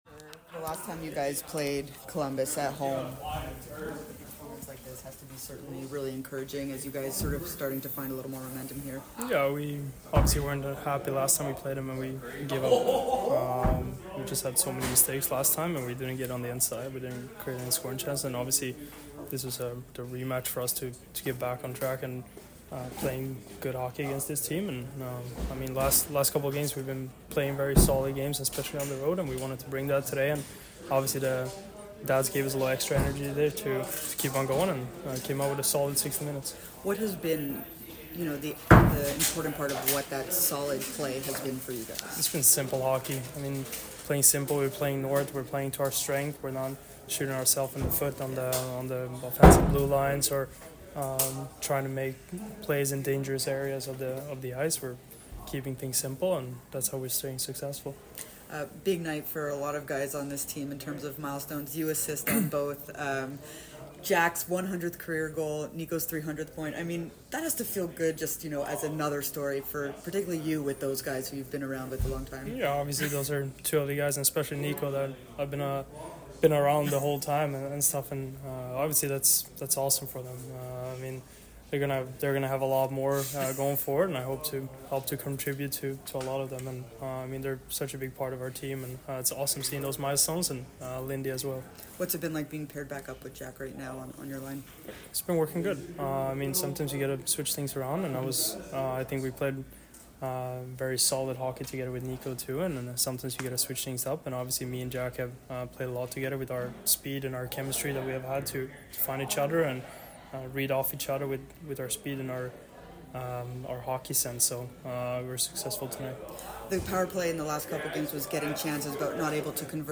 DEVILS POST-GAME AUDIO INTERVIEWS